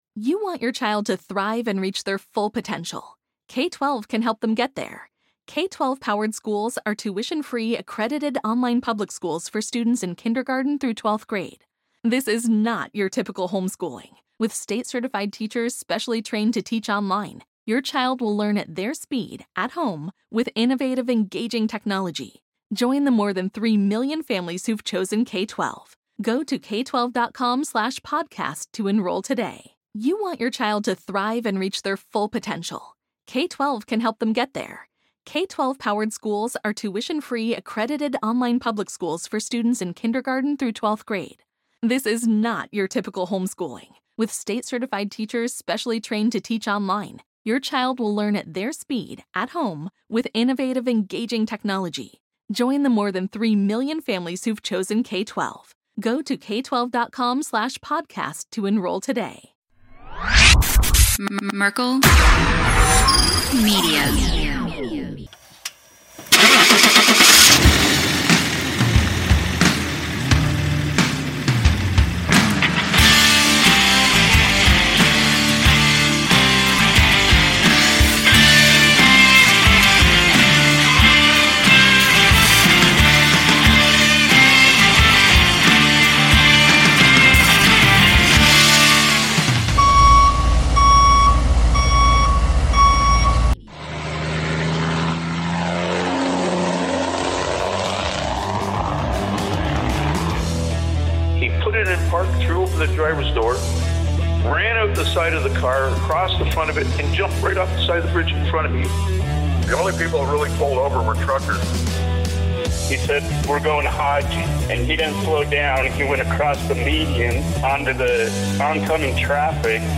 the guys are joined in studio